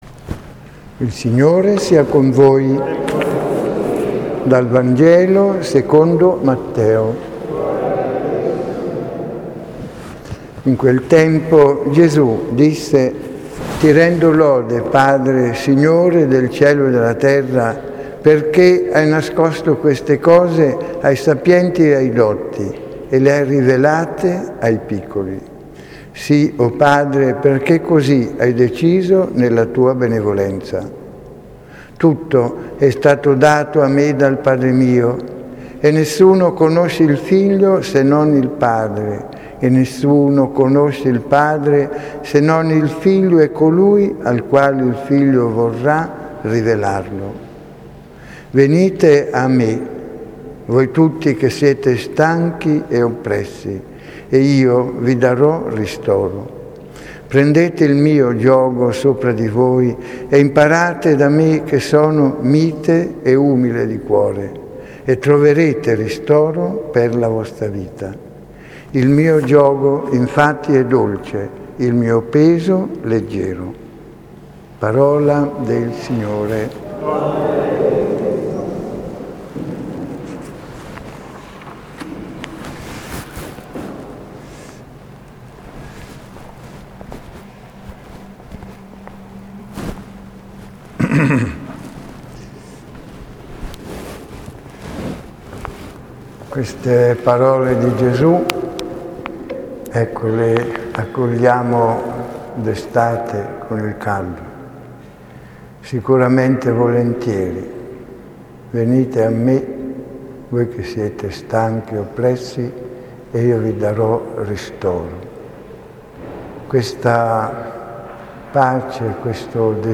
l'omelia di questa Domenica